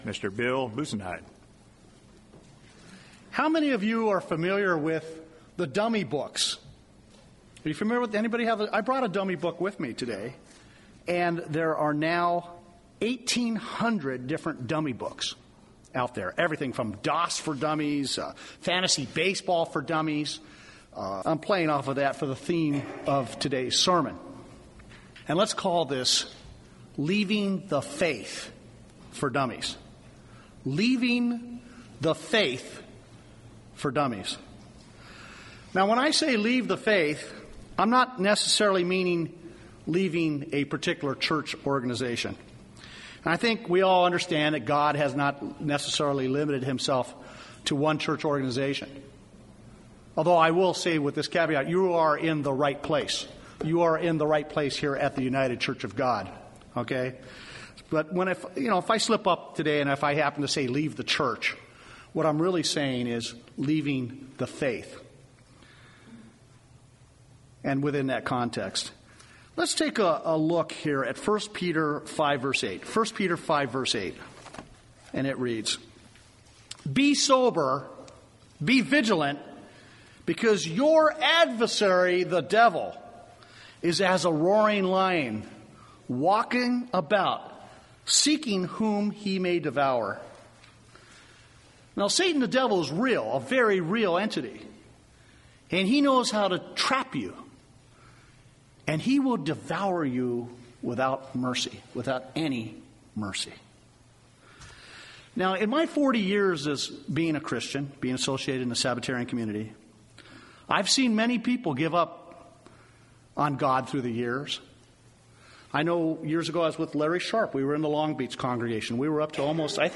Given in Redlands, CA San Diego, CA
UCG Sermon Studying the bible?